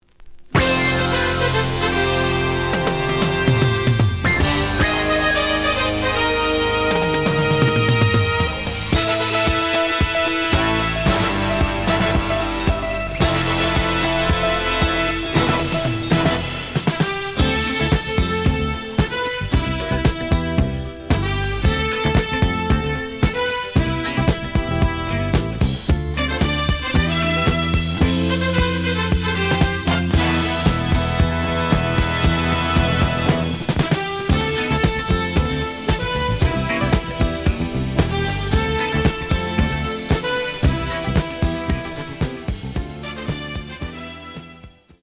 The audio sample is recorded from the actual item.
●Format: 7 inch
●Genre: Jazz Funk / Soul Jazz